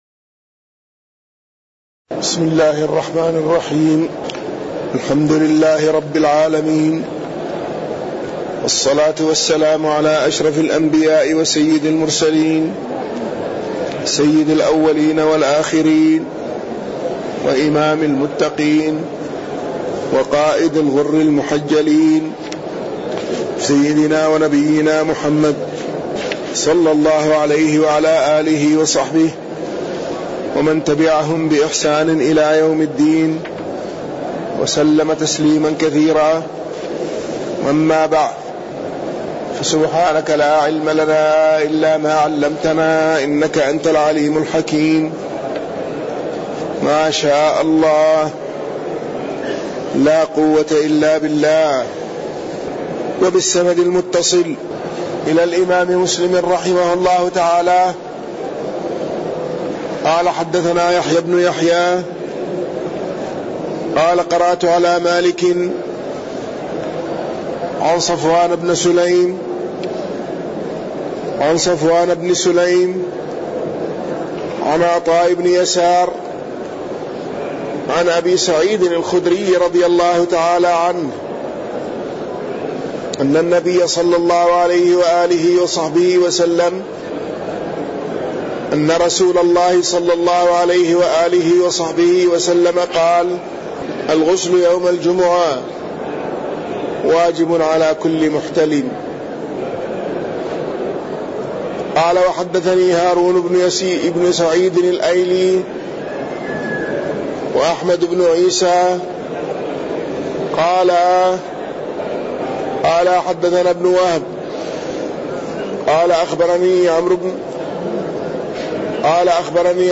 تاريخ النشر ٢٥ جمادى الآخرة ١٤٣١ هـ المكان: المسجد النبوي الشيخ